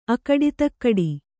♪ akaḍitakaḍi